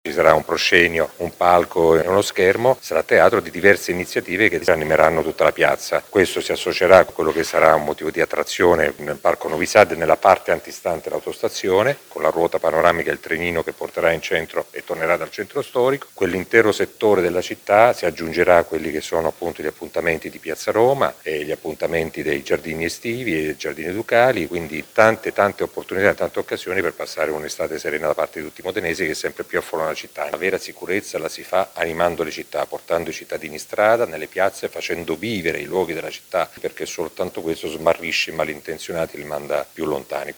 Facciamo vivere le piazze per portare sicurezza, dice il sindaco Massimo Mezzetti: